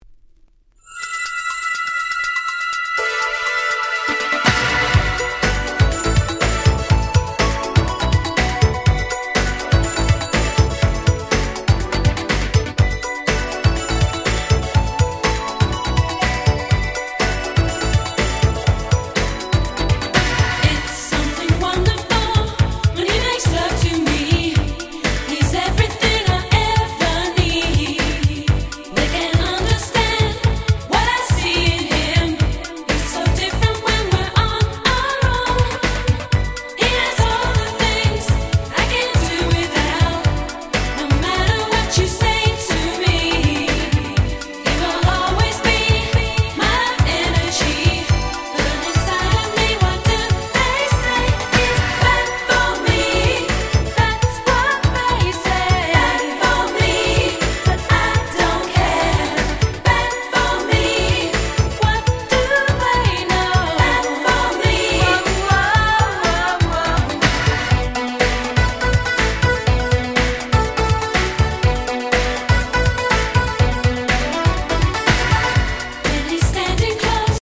88' Super Hit Euro Beat / Disco !!